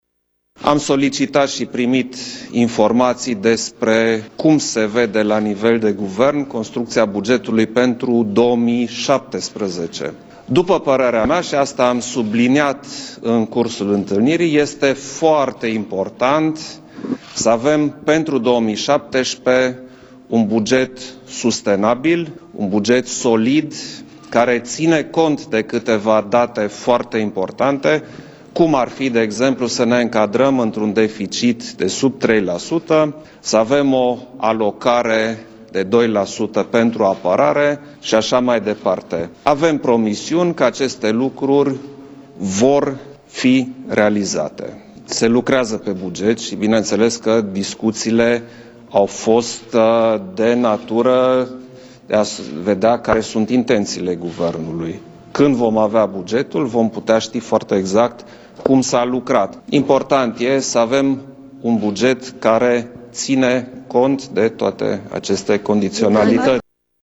Șeful statului a afirmat după întâlnirea cu premierul Sorin Grindeanu, că foarte important să avem un buget sustenabil, care să țină cont de câteva date foarte importante, printre care încadrarea într-un deficit sub 3% și alocarea a 2% pentru Apărare: